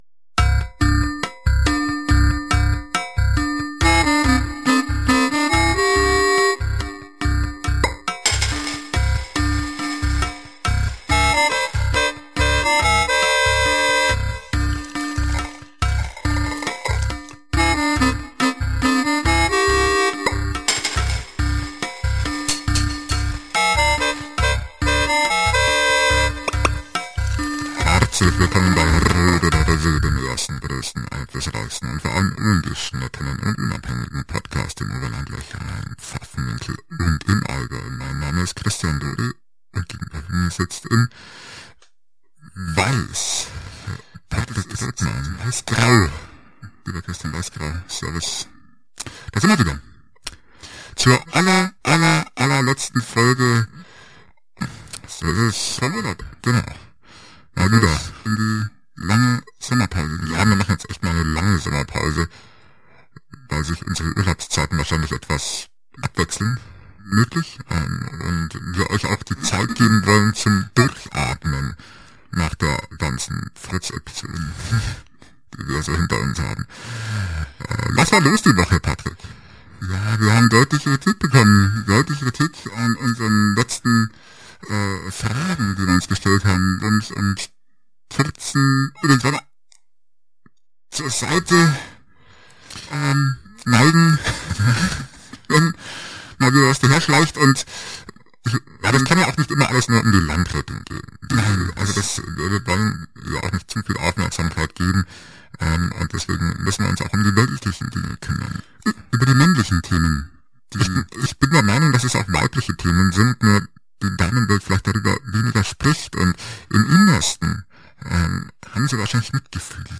Die Fehler sind gefunden aber diese Episode ist leider nicht mehr zu retten.